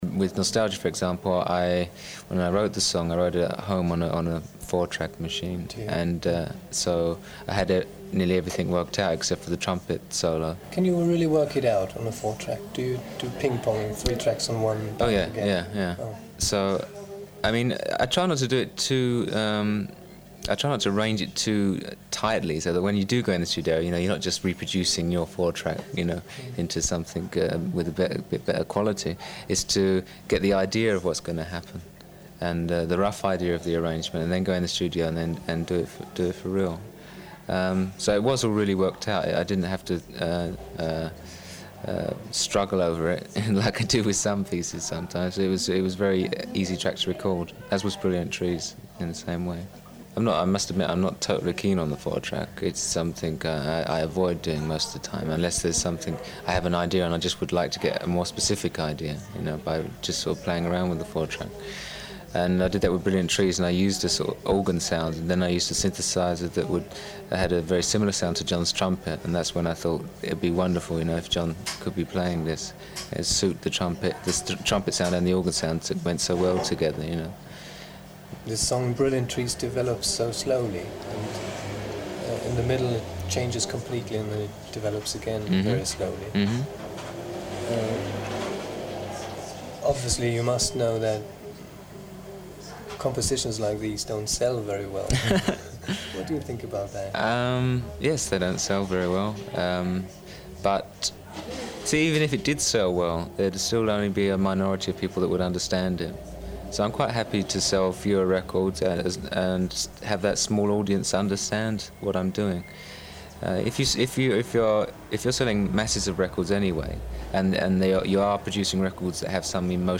1984년 인터뷰에서 4트랙 홈 레코딩에 대해 이야기하는 실비안